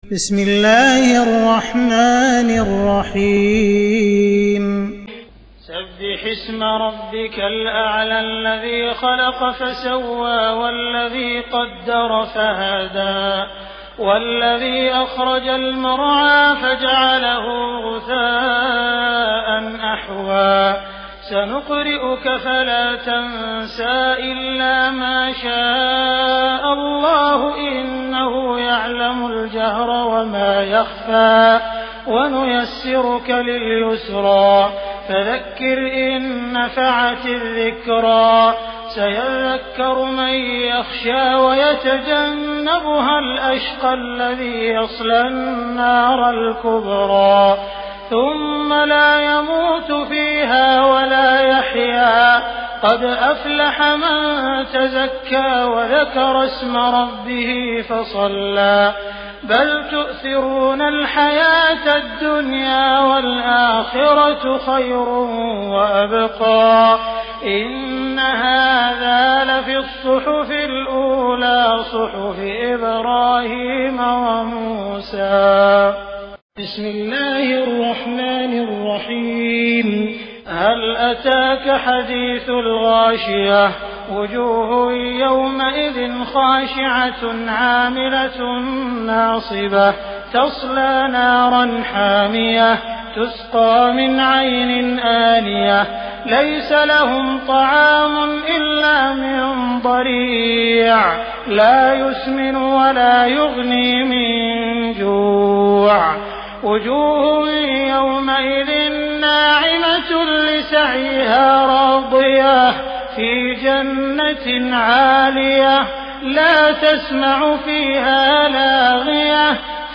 تراويح ليلة 29 رمضان 1418هـ من سورة الأعلى الى الناس Taraweeh 29 st night Ramadan 1418H from Surah Al-A'laa to An-Naas > تراويح الحرم المكي عام 1418 🕋 > التراويح - تلاوات الحرمين